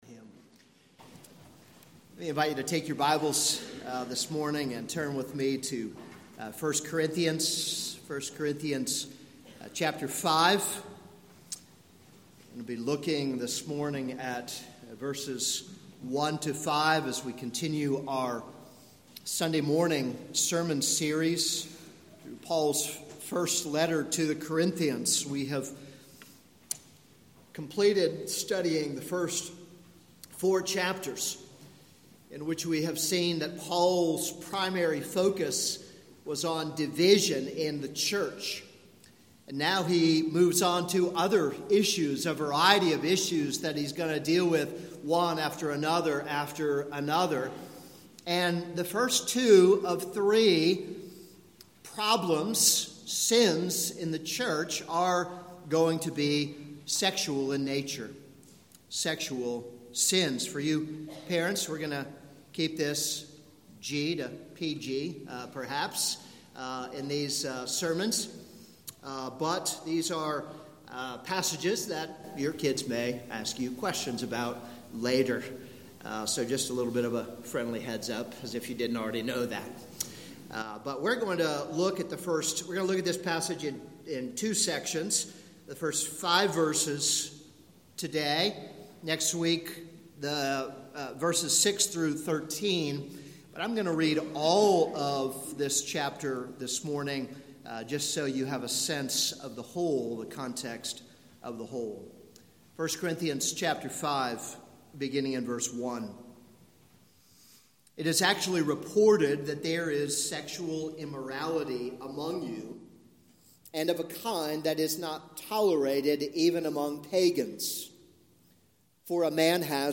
This is a sermon on 1 Corinthians 5:1-5.